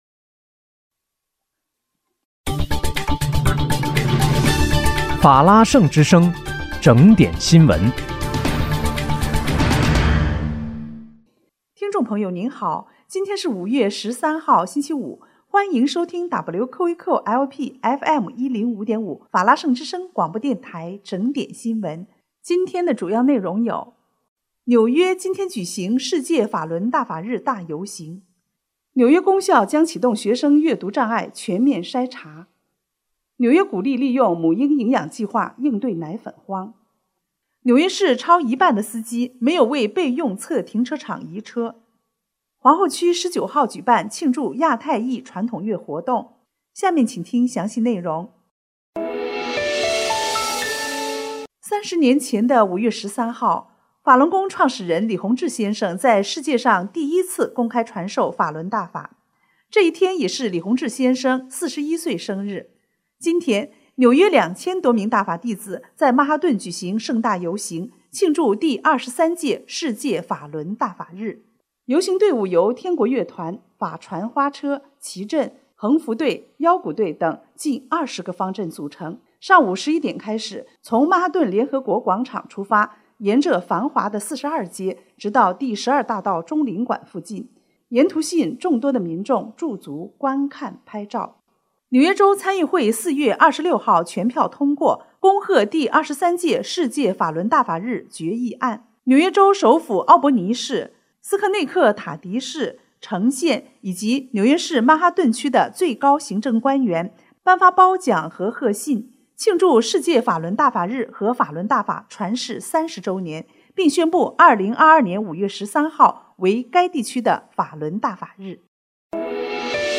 5月13日（星期五）纽约整点新闻